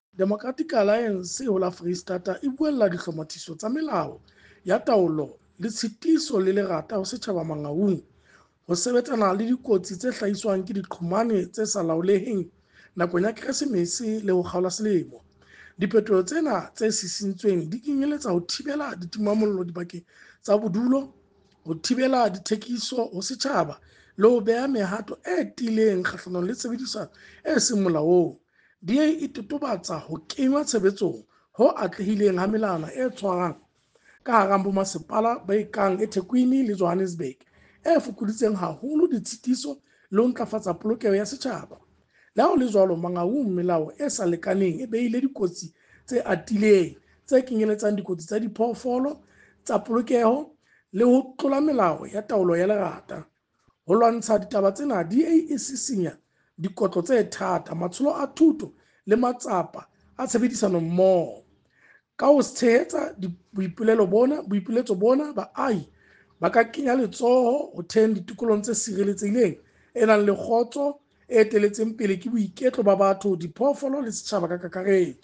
Sesotho soundbite by Kabelo Mooreng MPL